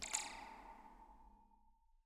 Percussion
zap2_v1.wav